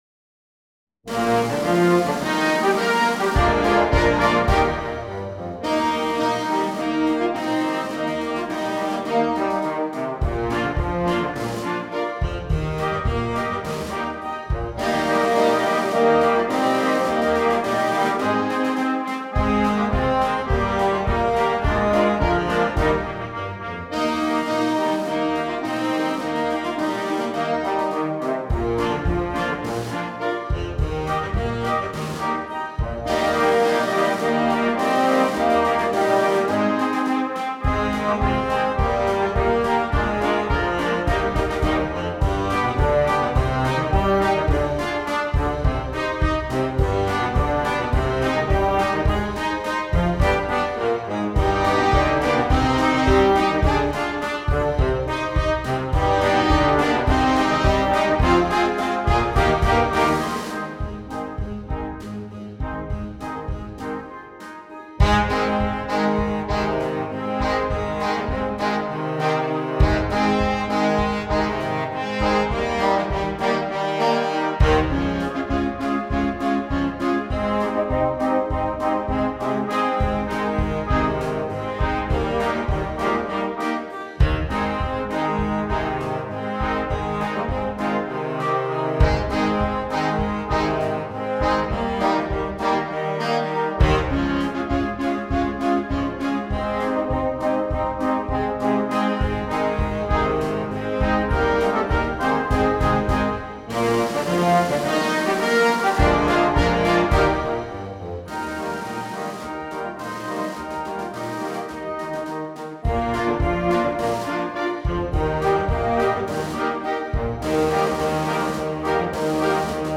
March humoresque (einmal anders) für Jugendblasorchester…